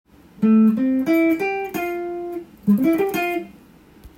Dm7のコード上で使えるフレーズをtab譜にしてみました。
２～３本ほどの弦をさらっと弾くフレーズになります。